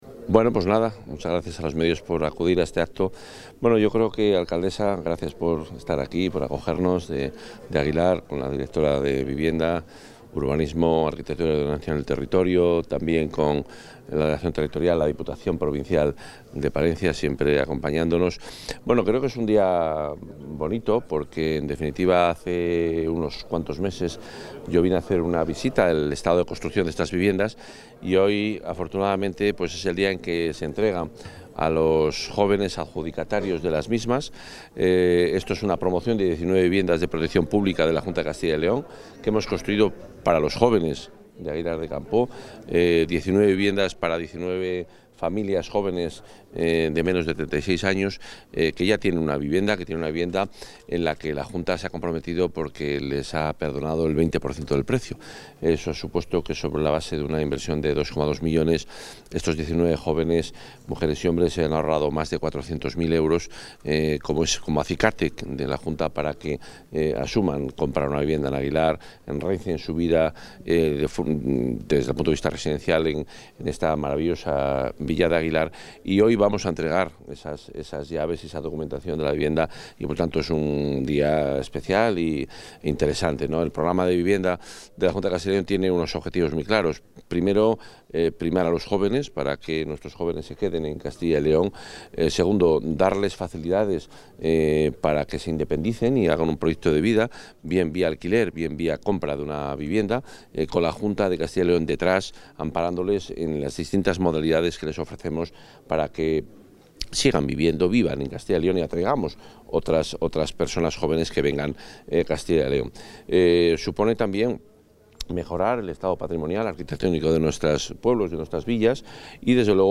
Intervención del consejero.
El consejero de Medio Ambiente, Vivienda y Ordenación del Territorio, Juan Carlos Suárez-Quiñones, ha entregado las llaves de las viviendas de protección oficial construidas a través de Somacyl y destinadas a la venta en Aguilar de Campoo. La bonificación global aplicada a las promociones asciende a 403.955 euros, ya que a los precios de venta de las 19 viviendas se les ha aplicado una reducción del 20 %, debido a que los compradores tienen menos de 36 años.